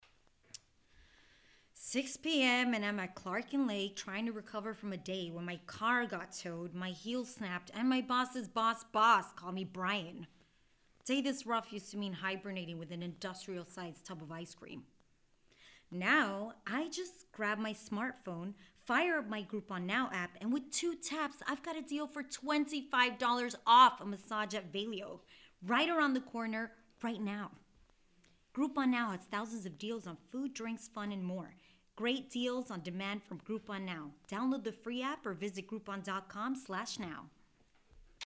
Voiceover Samples:
Spanish-English Voiceover for Institutional / Government PSA